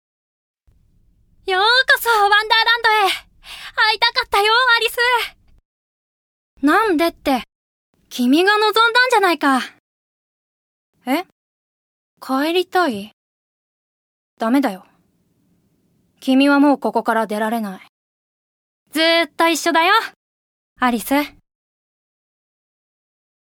◆名乗り+少女◆
◆ナチュラルな女性◆
◆ファンタジーの少年◆